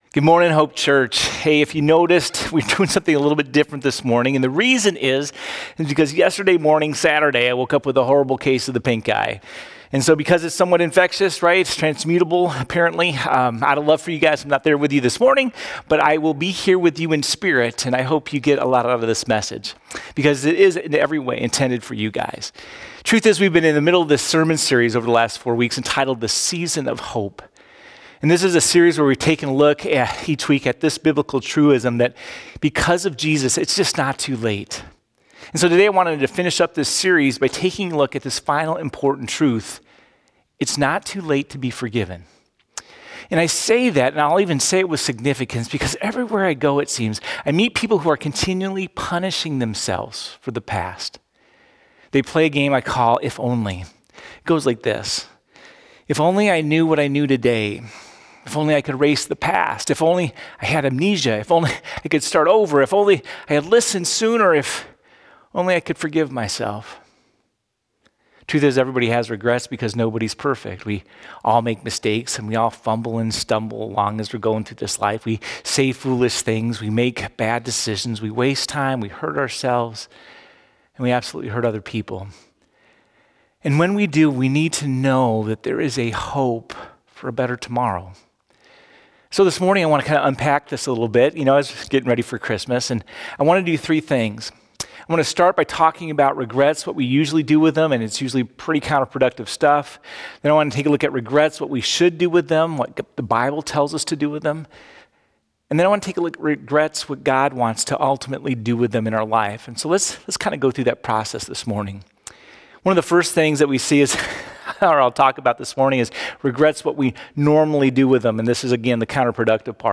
12-15-Sermon.mp3